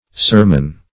Sermon \Ser"mon\, v. t.